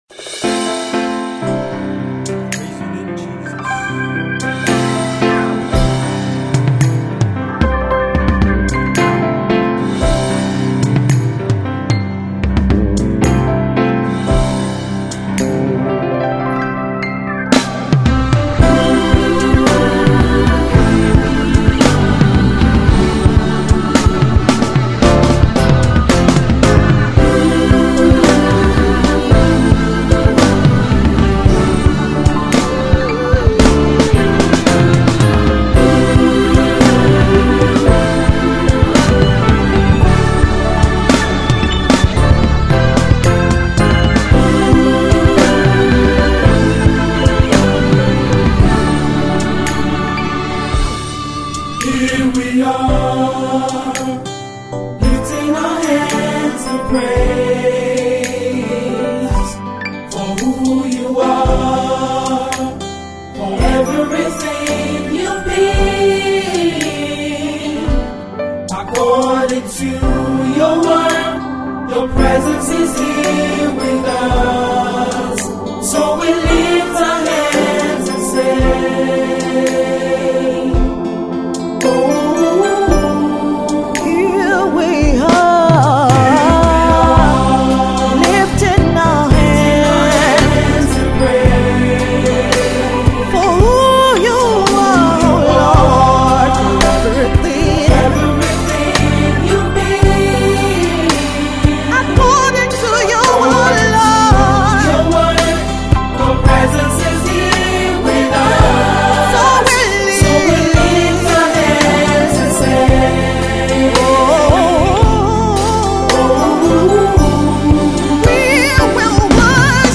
made appropriate for Congregational Worship.
a medley of two songs